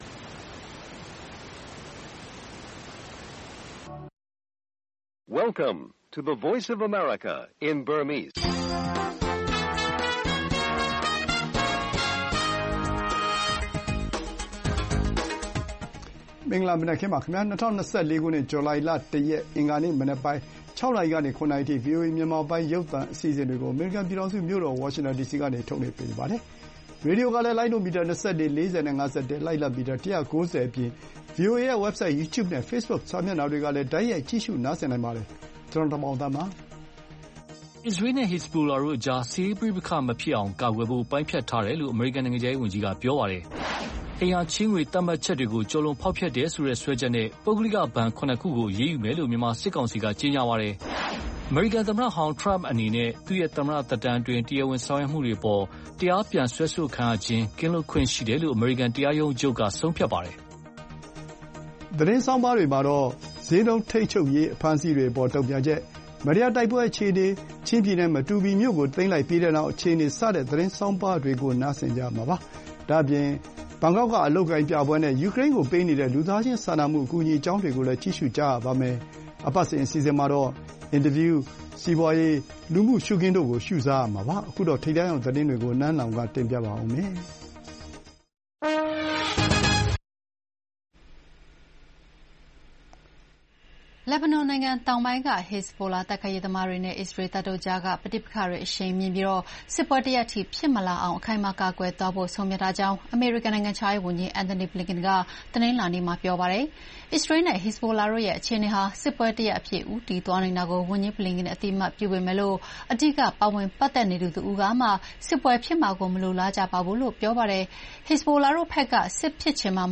ဗွီအိုအေမြန်မာနံနက်ခင်း(ဇူလှိုင် ၂၊ ၂၀၂၄) ကန်တရားရုံးချုပ်ဆုံးဖြတ်ချက် Trump တရားစွဲခံရမှု တစုံတရာကင်းလွတ်ခွင့်ရရှိ၊ အိမ်ရာချေးငွေကိစ္စစွဲချက်နဲ့ ပုဂ္ဂလိကဘဏ် ၇ ခု အရေးယူမယ်လို့ စစ်ကောင်စီထုတ်ပြန် စတဲ့ သတင်းတွေနဲ့ အပတ်စဉ်ကဏ္ဍတွေမှာ စီးပွားရေး၊ လူမှုရှုခင်းတို့ ထုတ်လွှင့်ပေးပါမယ်။